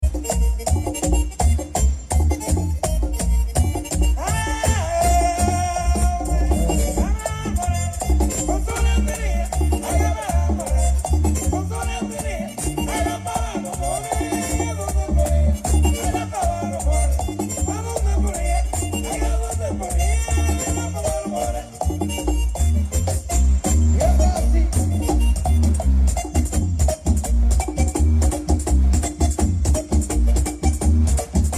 Merengue Típico